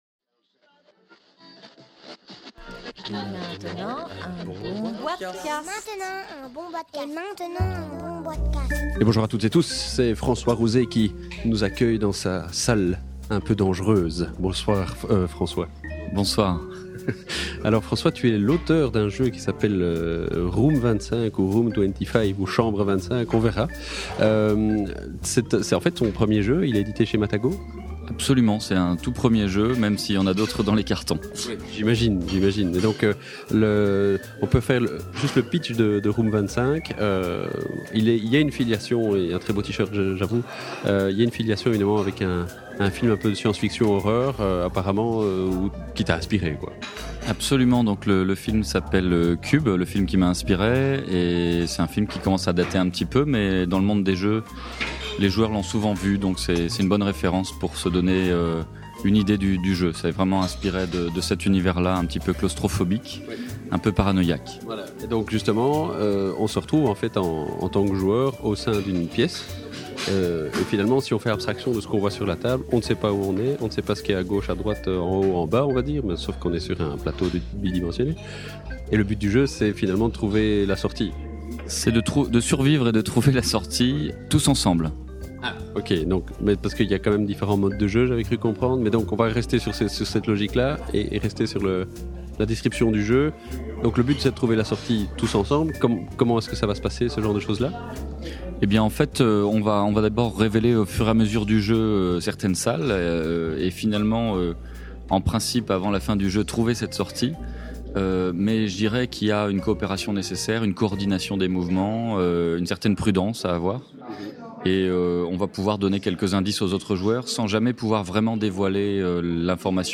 enregistré au Salon international du Jeu de Société de Essen – Octobre 2012